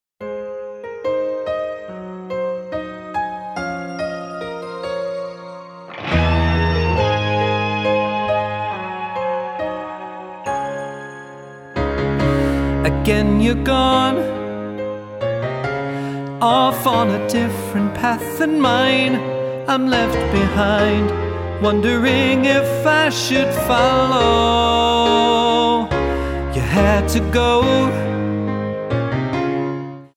--> MP3 Demo abspielen...
Tonart:Ab Multifile (kein Sofortdownload.
Die besten Playbacks Instrumentals und Karaoke Versionen .